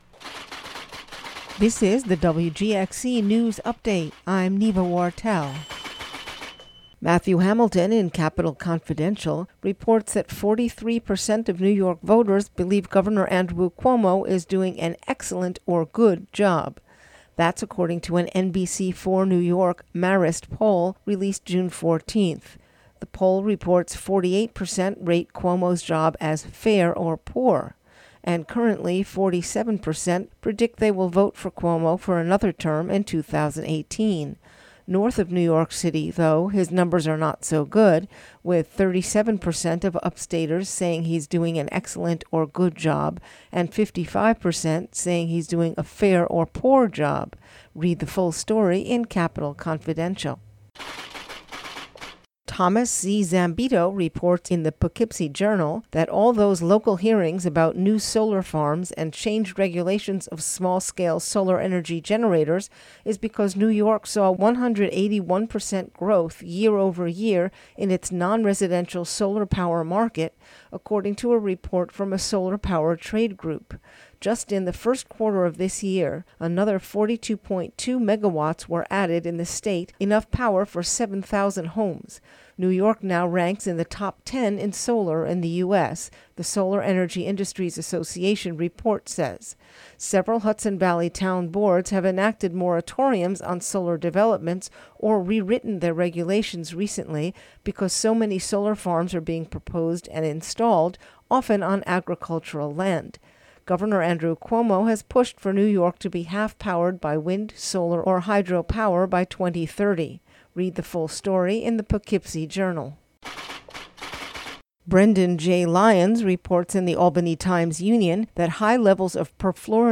Former District 19 Congressperson John Hall, talking with WGXC, said June 14 that paid protesters are not calling the Congressperson.